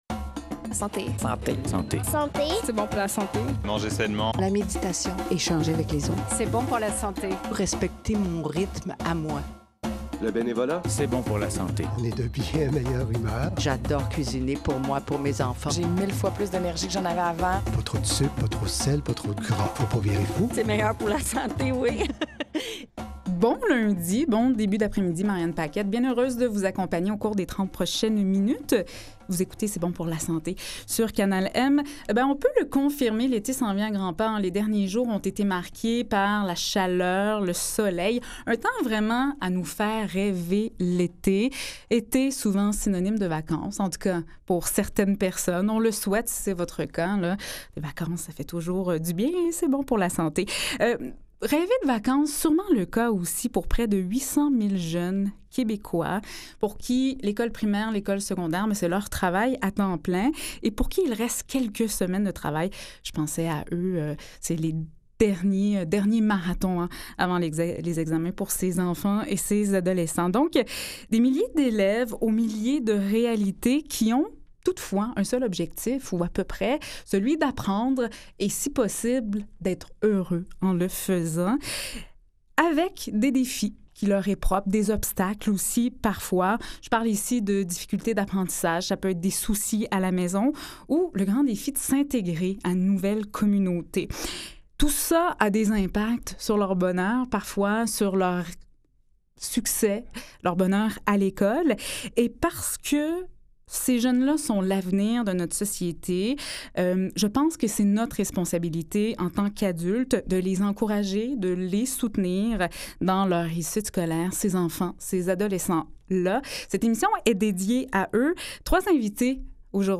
Lundi 30 mai 2016 – Un magazine de services axé sur la promotion de la santé et de saines habitudes de vie. Au menu : conseils, stratégies de prévention, outils et ressources pour conserver ou retrouver sa forme physique et mentale.